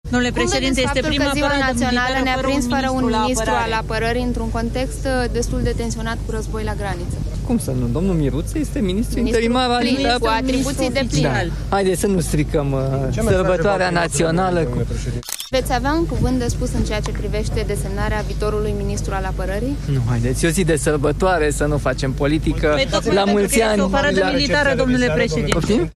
„Să nu stricăm sărbătoarea națională” – a fost răspunsul lui Nicușor Dan la întrebările jurnaliștilor legate de situația de la vârful Ministerului Apărării.